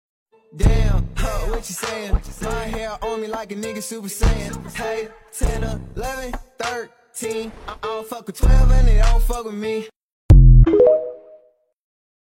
Heheh Mp3 Sound Effect